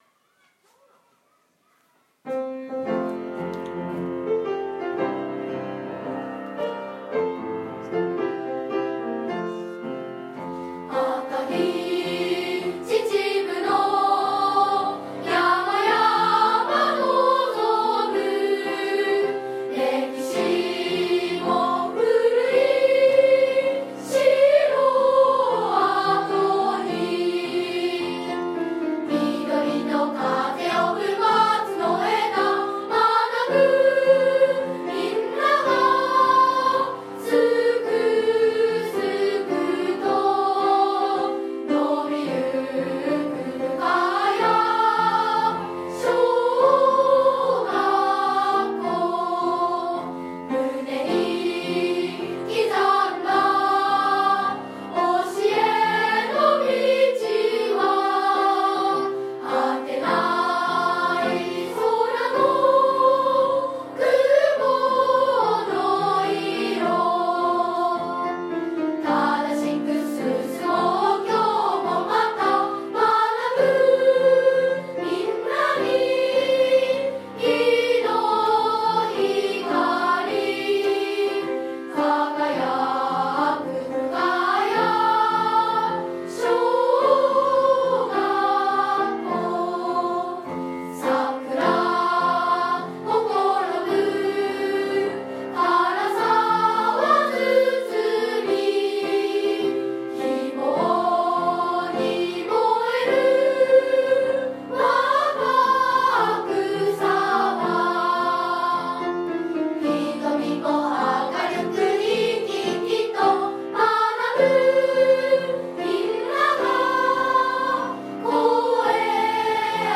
実際の歌も子供たちの声で聞くことができますので、何回も聞いて歌えるようにしておけるといいですね。
校歌（歌入り）←クリック